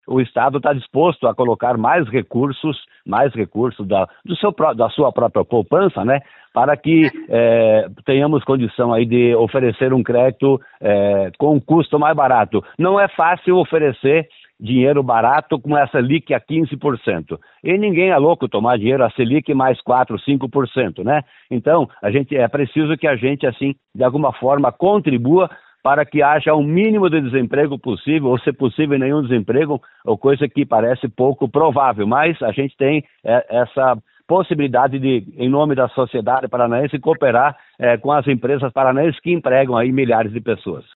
O secretário de Estado da Fazenda, Norberto Ortigara, disse em entrevista à CBN Curitiba que o Paraná está disposto a colocar mais recursos com o objetivo de ajudar empresários afetados pela taxação.